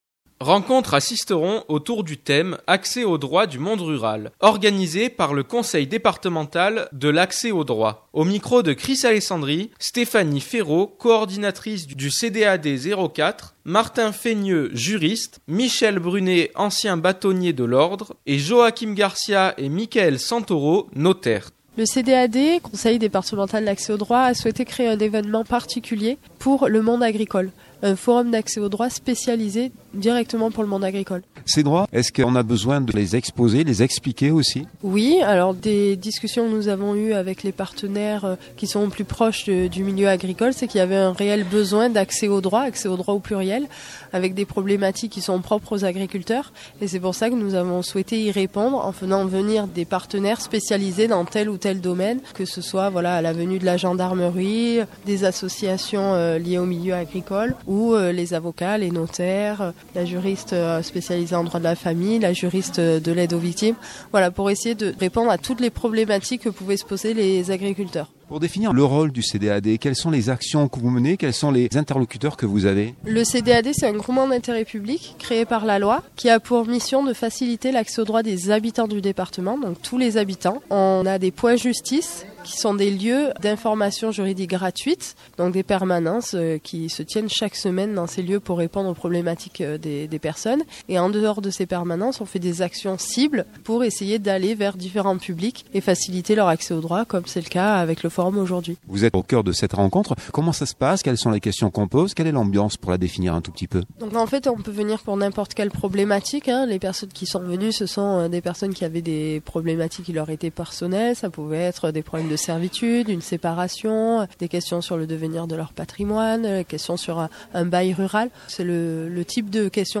2023-12-18 - Sisteron-CDAD-Forum MP3.mp3 (11.06 Mo) C’est une première pour les Alpes de Haute Provence, avec dernièrement un forum sur l’accès aux droits du monde agricole qui s’est déroulé à Sisteron à la salle Alain Prieur. Le CDAD: Conseil Départemental de l’Accès au Droit des Alpes de Haute-Provence a permis la rencontre avec plusieurs de ses partenaires comme la Chambre d’Agriculture, les syndicats agricoles, la MSA, mais aussi les avocats du barreau, ou encore la gendarmerie.